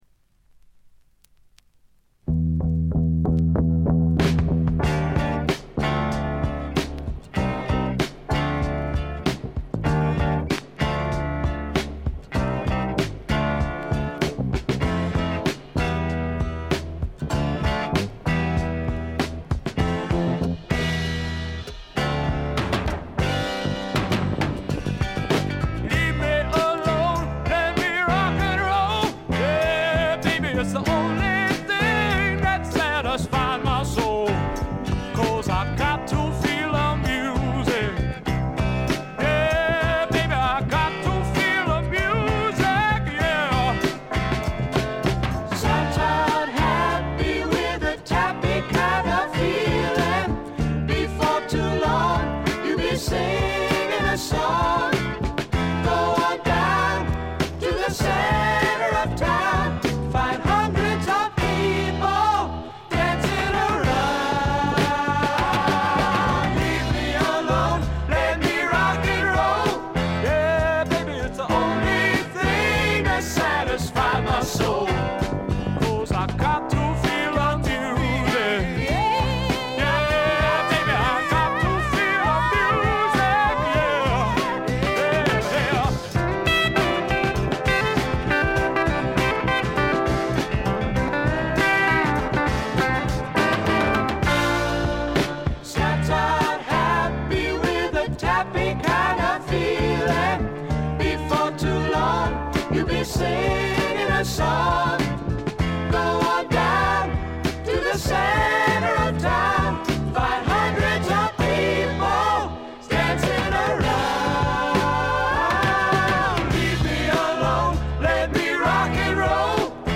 ホーム > レコード：英国 SSW / フォークロック
静音部でチリプチが聞かれますが気になるノイズはありません。
試聴曲は現品からの取り込み音源です。